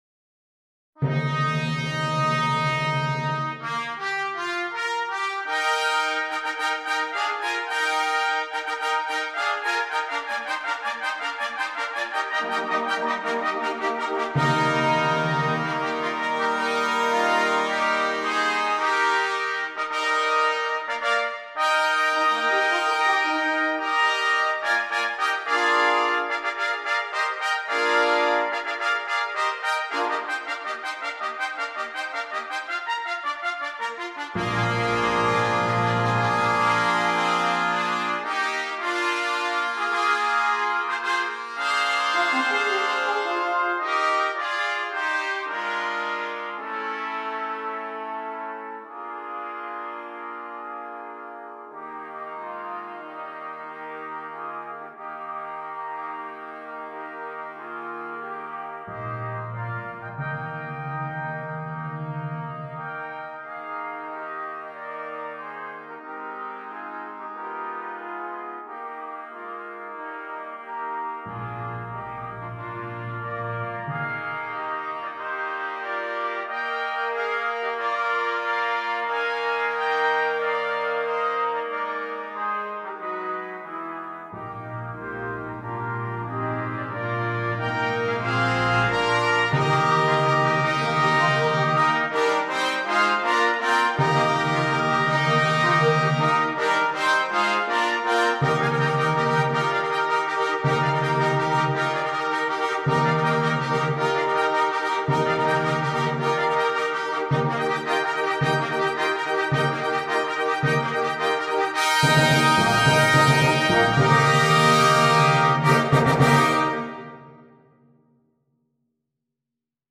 Brass
9 Trumpets and optional Timpani